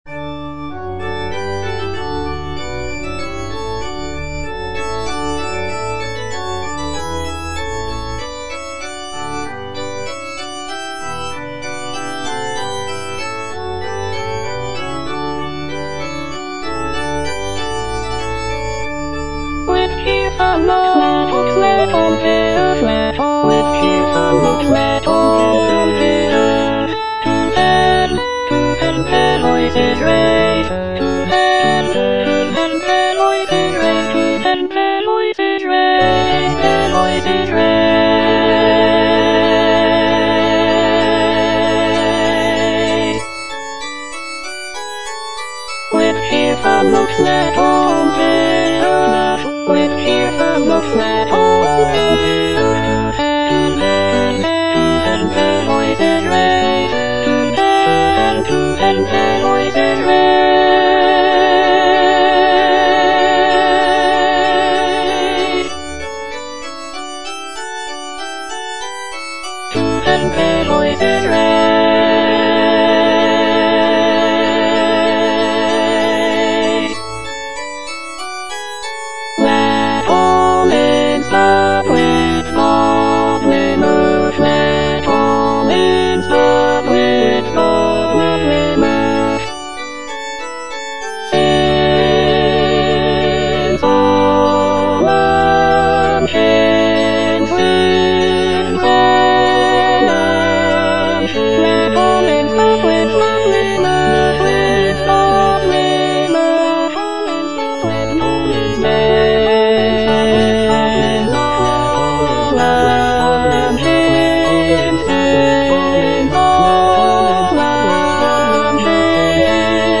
G.F. HÄNDEL - O PRAISE THE LORD WITH ONE CONSENT - CHANDOS ANTHEM NO.9 HWV254 (A = 415 Hz) With cheerful notes - Alto (Emphasised voice and other voices) Ads stop: auto-stop
The work is typically performed with historical performance practices in mind, including the use of a lower tuning of A=415 Hz to replicate the sound of Handel's time.